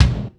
Kick_13_b.wav